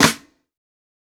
TC2 Snare 11.wav